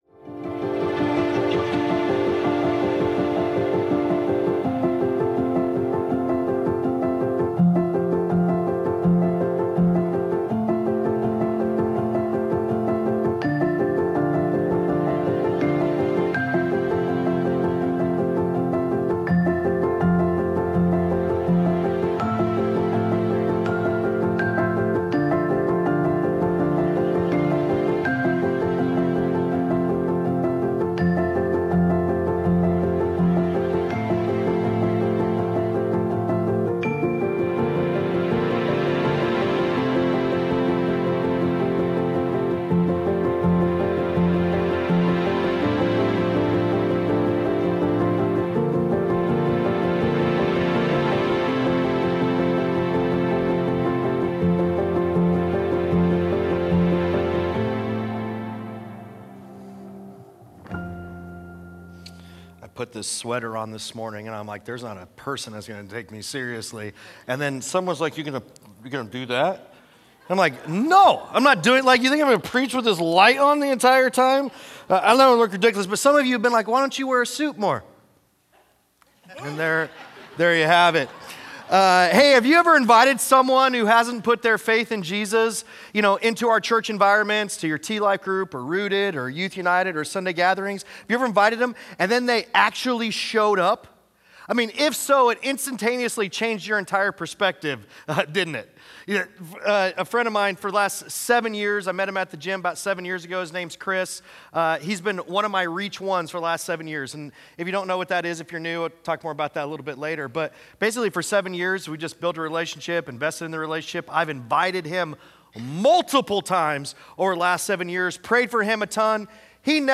Sunday Sermons Lost and Found, Week 3: "Open Arms" Dec 08 2025 | 00:39:09 1x 00:00 / 00:39:09 Subscribe Share Apple Podcasts Spotify Overcast RSS Feed Share Link Embed